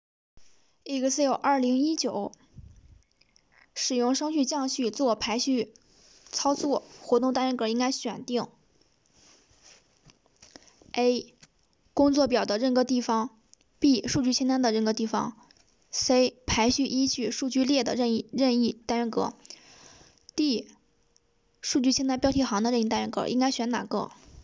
数据+术语+中英文：